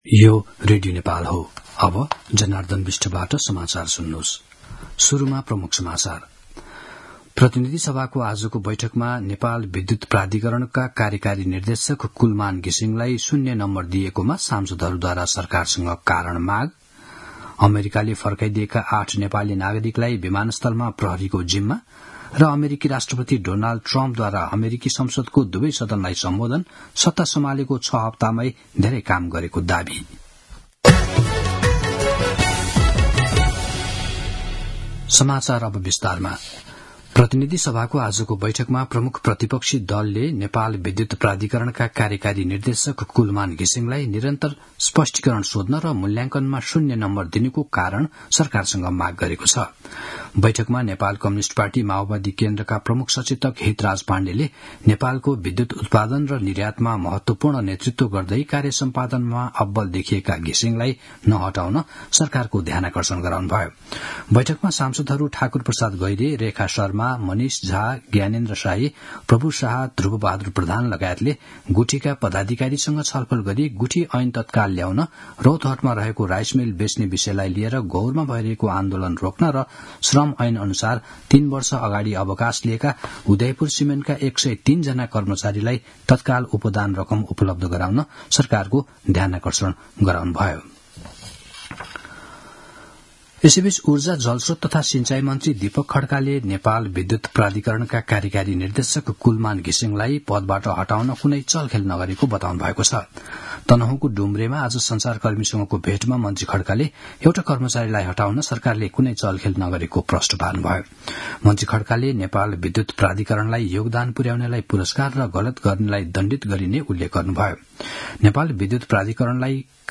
दिउँसो ३ बजेको नेपाली समाचार : २२ फागुन , २०८१